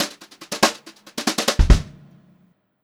144GVFILL1-L.wav